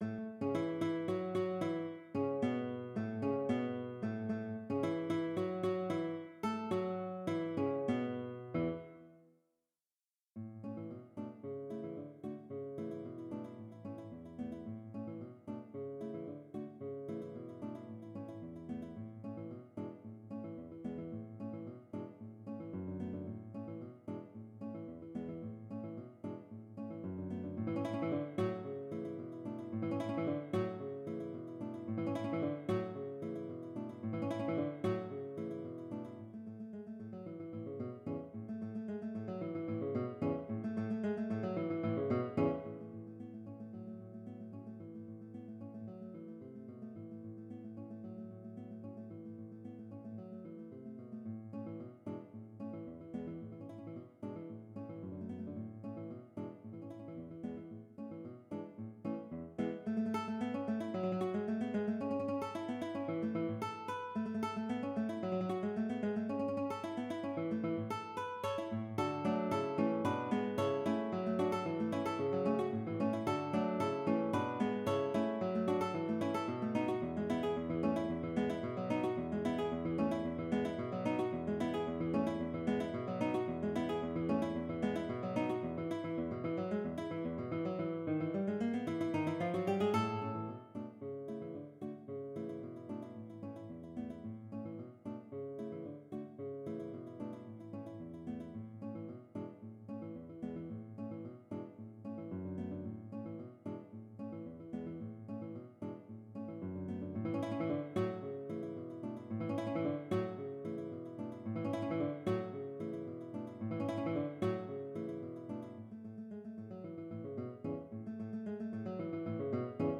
No parts available for this pieces as it is for solo piano.
Piano  (View more Advanced Piano Music)
Classical (View more Classical Piano Music)